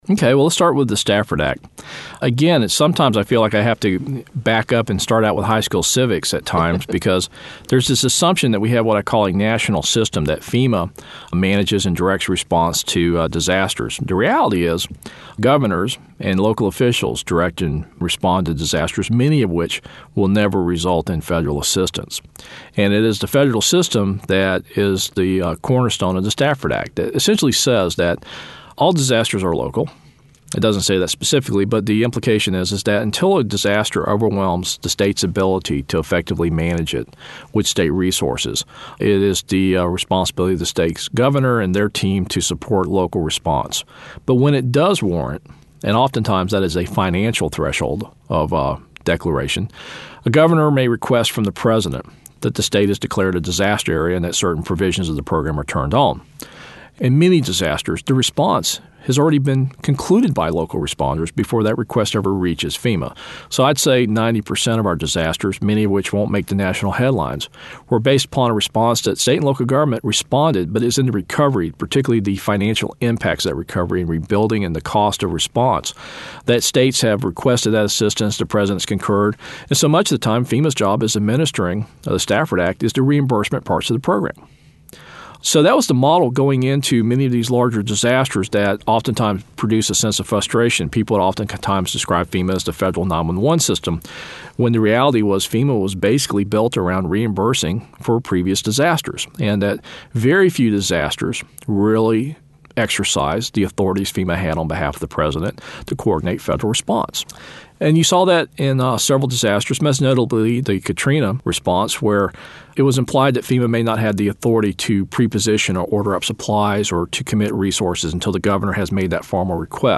A Conversation with W. Craig Fugate, FEMA Administrator